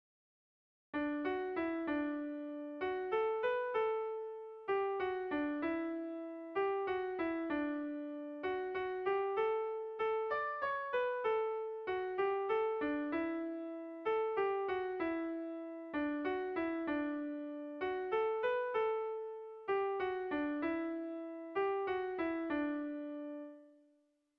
Kontakizunezkoa
ABDAB